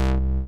noise24.ogg